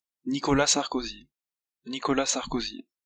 Nicolas Paul Stéphane Sarközy de Nagy-Bocsa (/sɑːrˈkzi/ sar-KOH-zee; French: [nikɔla pɔl stefan saʁkɔzi naʒi bɔksa]